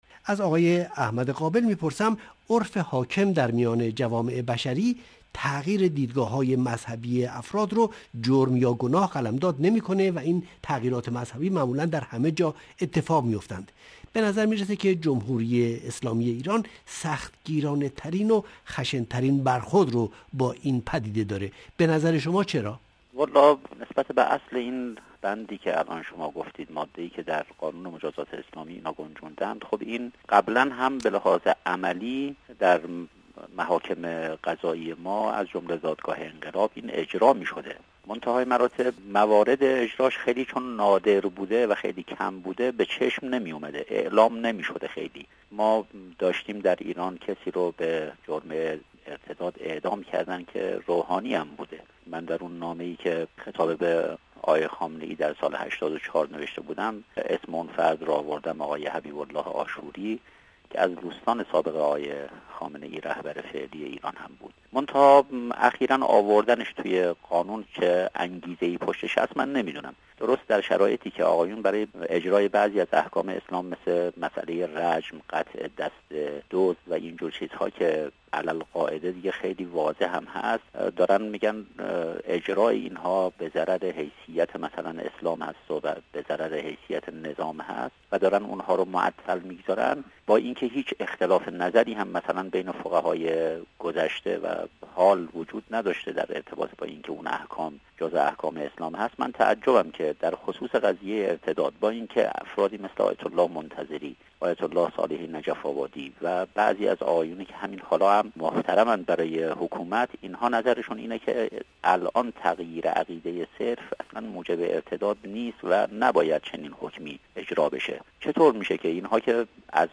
این میزگزد را بشنوید